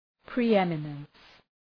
Προφορά
{pri:’emənəns} (Ουσιαστικό) ● υπεροχή